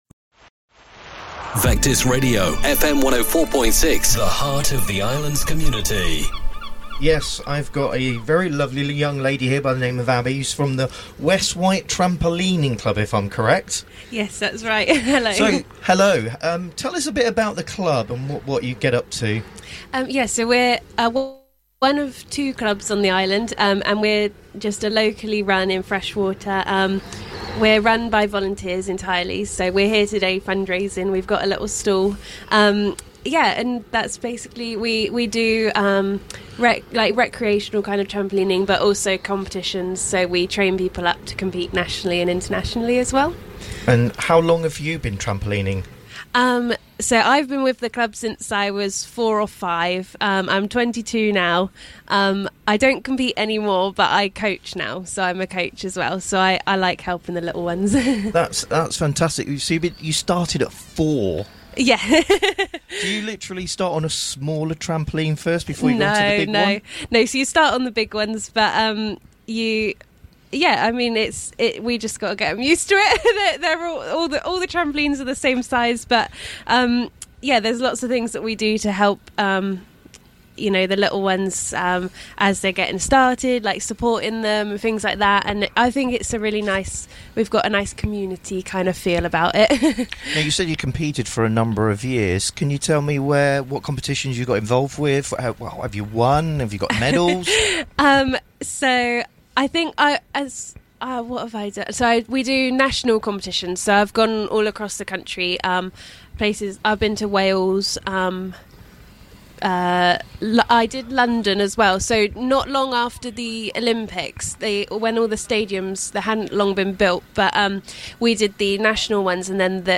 at The Chale Show 2025.